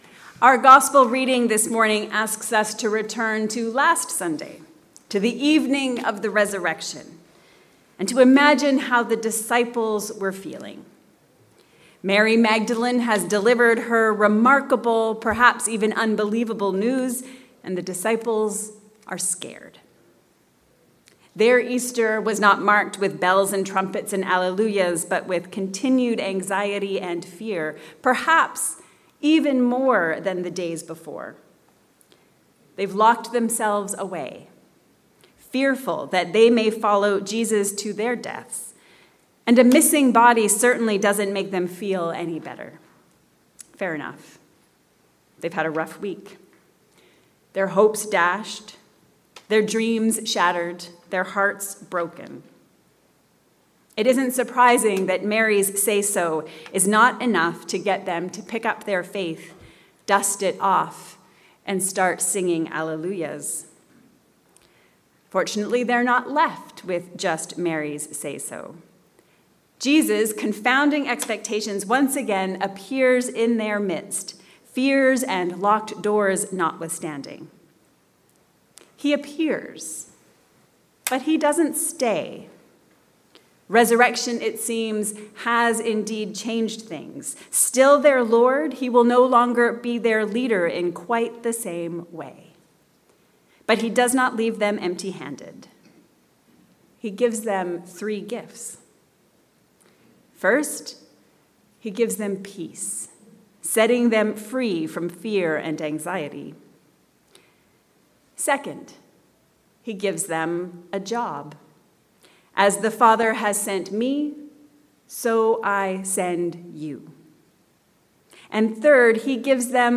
The tools for Easter living. A sermon for the 2nd Sunday of Easter